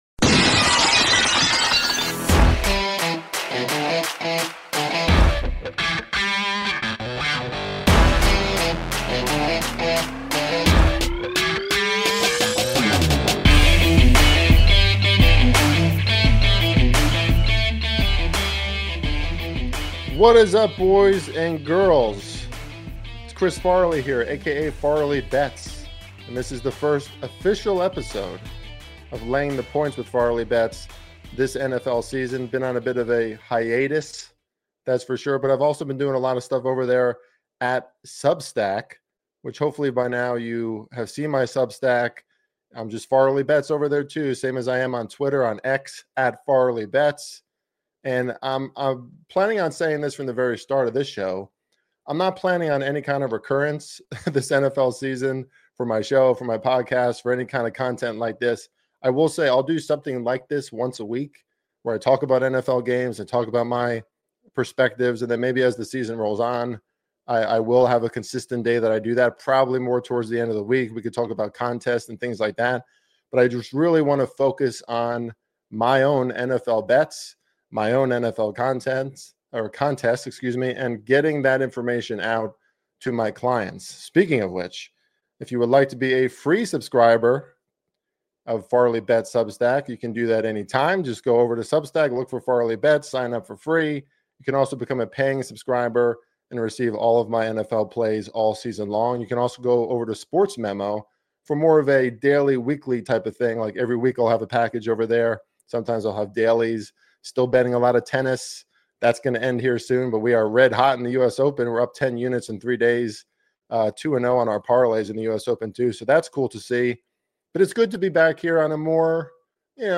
A Pre-Kickoff Conversation with NFL Betting Expert